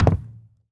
bowling_ball_land_02.wav